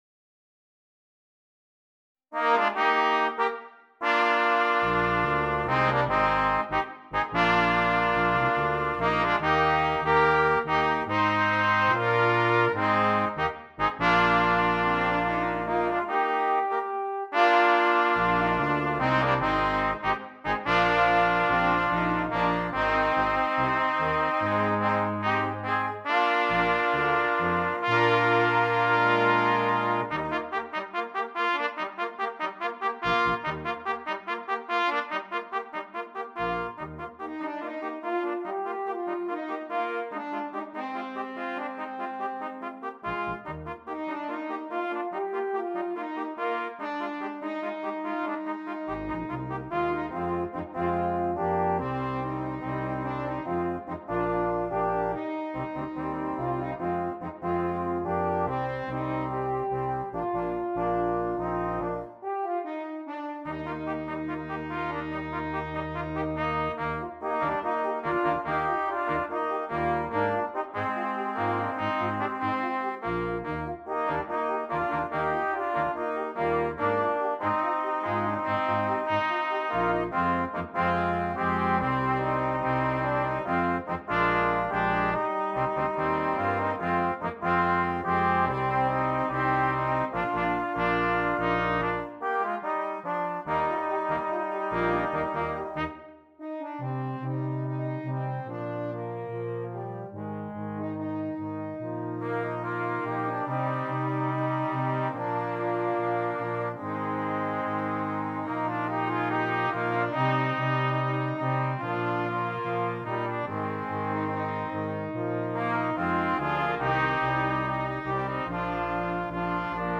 Brass Quintet
fun, upbeat piece